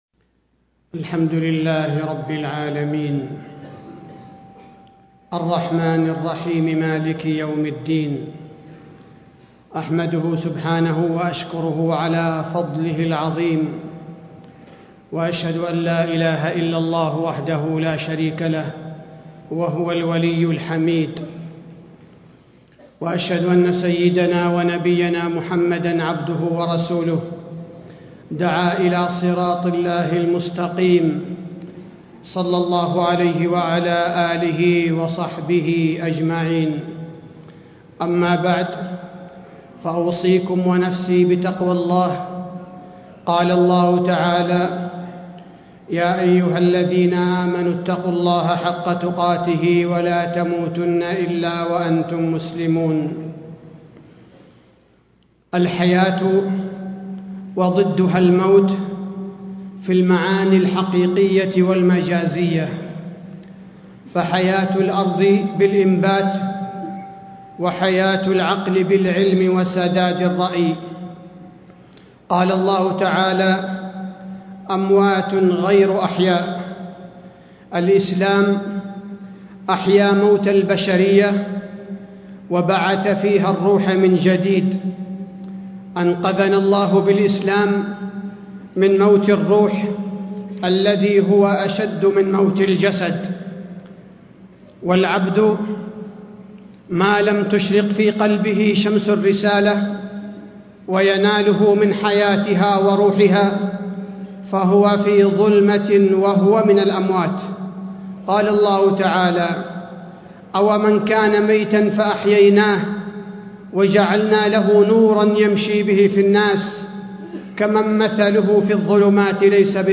تاريخ النشر ٦ جمادى الأولى ١٤٣٥ هـ المكان: المسجد النبوي الشيخ: فضيلة الشيخ عبدالباري الثبيتي فضيلة الشيخ عبدالباري الثبيتي الحياة والموت في الكتاب والسنة